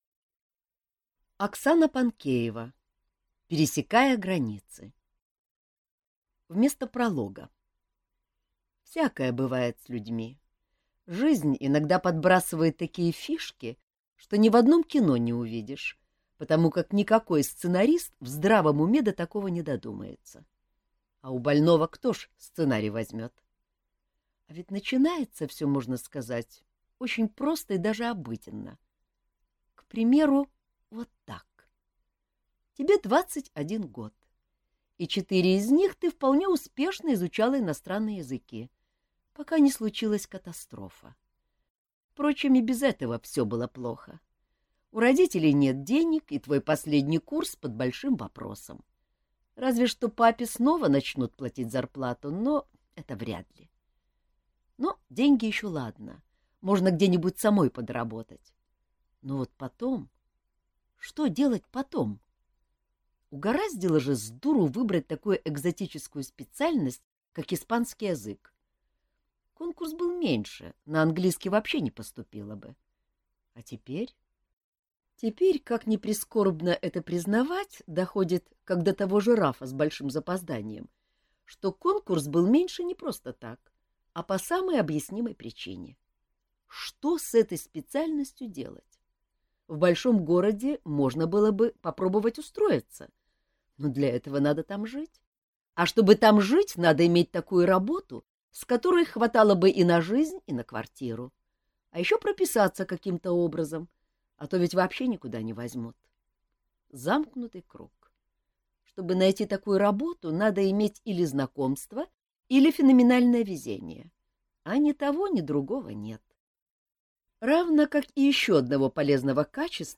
Аудиокнига Пересекая границы | Библиотека аудиокниг